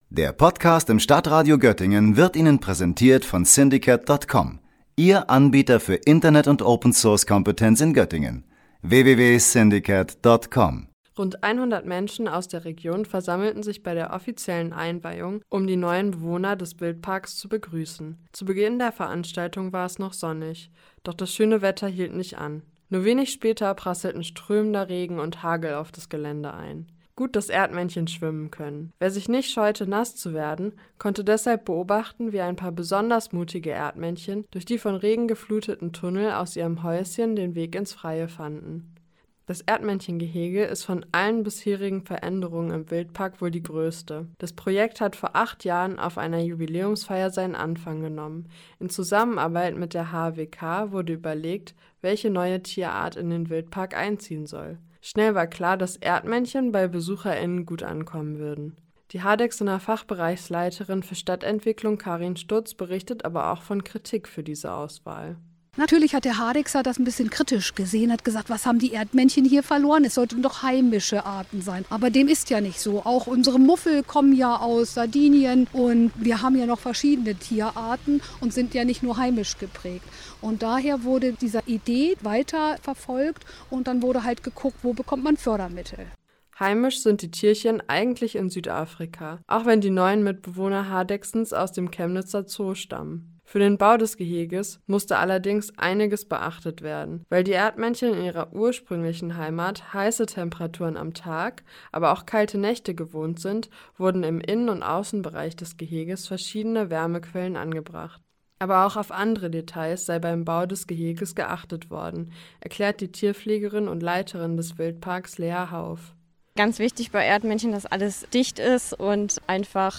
Zur Einweihung war unter anderem auch der Bürgermeister der Stadt Hardegsen, Lars Gärner, anwesend.